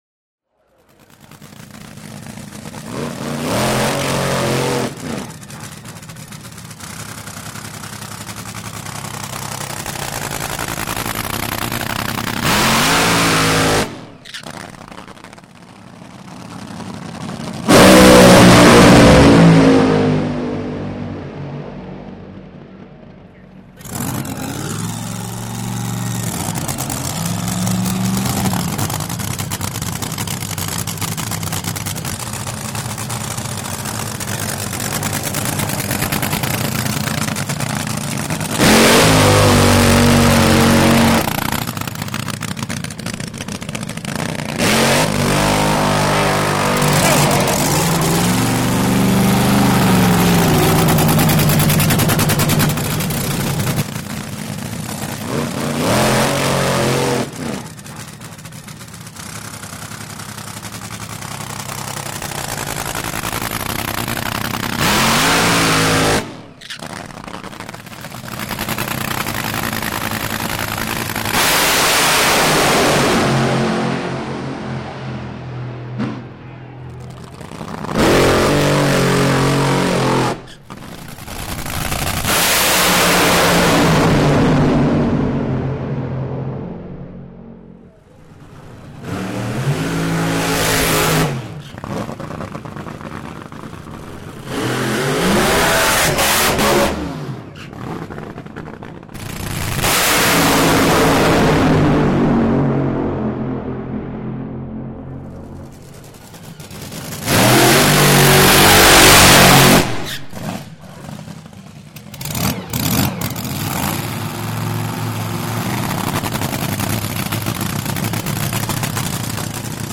TopFuelDragster.mp3